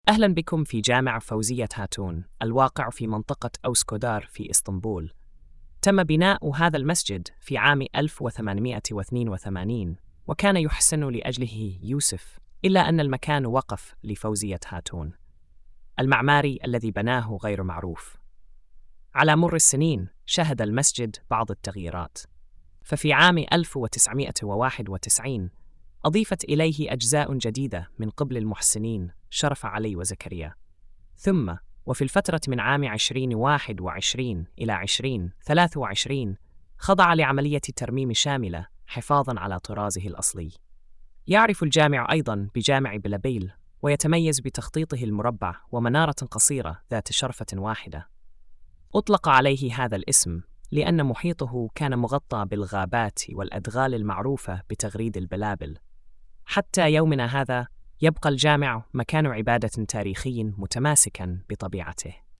السرد الصوتي